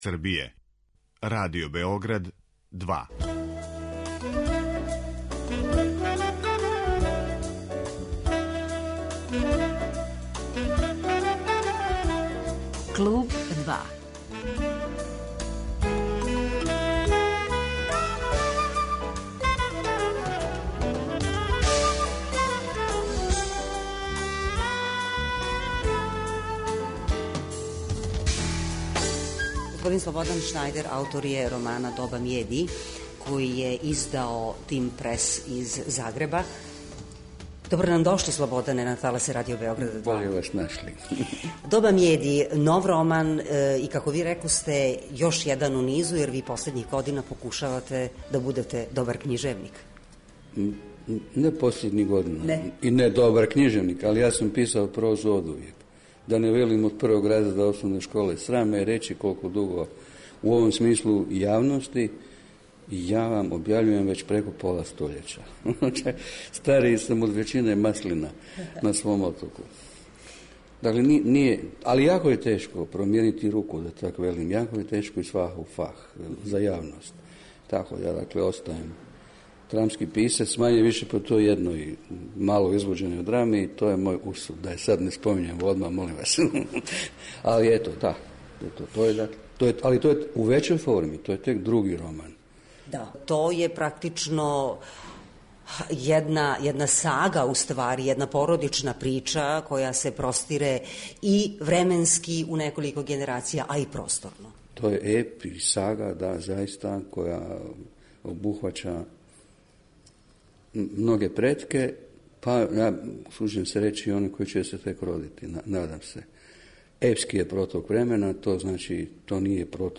Гост је Слободан Шнајдер, писац из Хрватске.